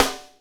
Index of /90_sSampleCDs/Northstar - Drumscapes Roland/DRM_Funk/SNR_Funk Snaresx
SNR FNK S06L.wav